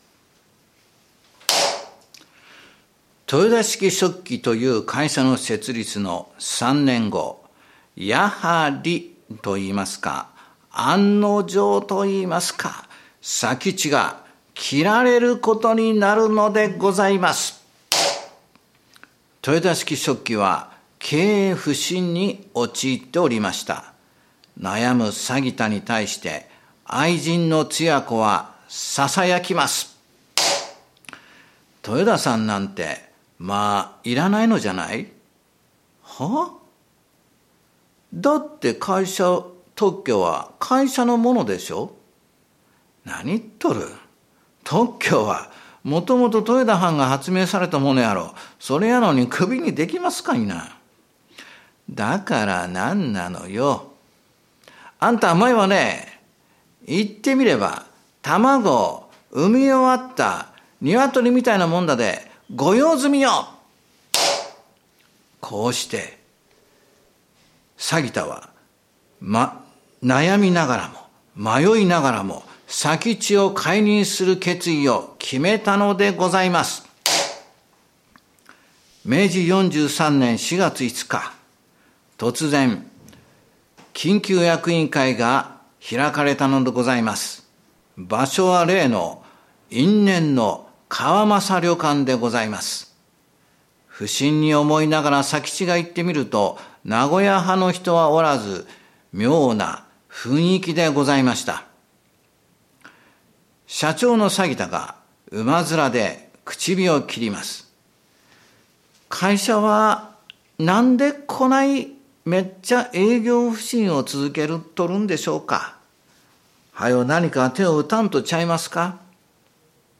講談 やらまいか豊田佐吉傳
注：●は、扇子を打つ音でございます。